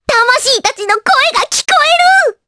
Rephy-Vox_Skill6_jp.wav